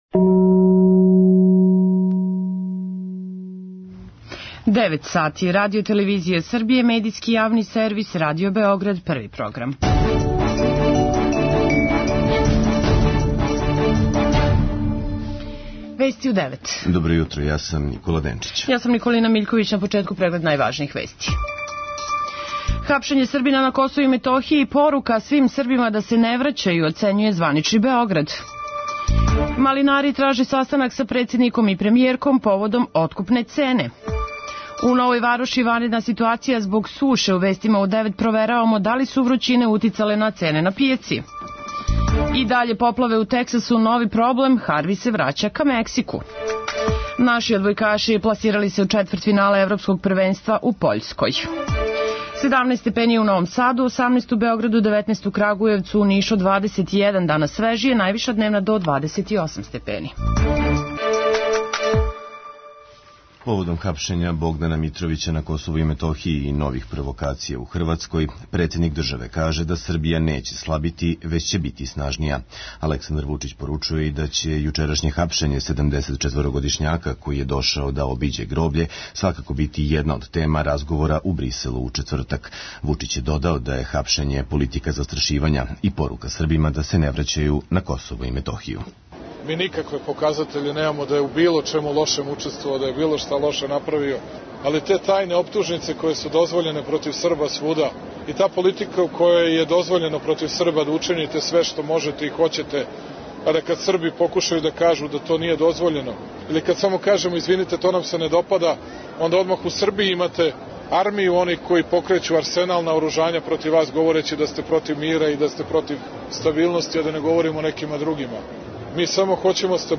Вести у 9